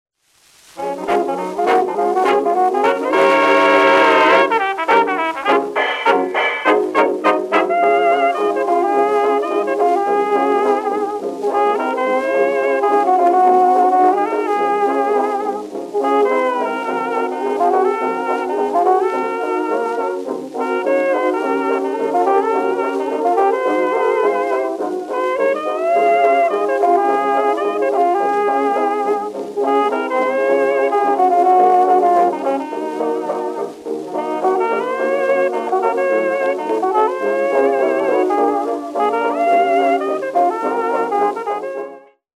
Fox trot
8kHz Low Pass (6dB/Octave)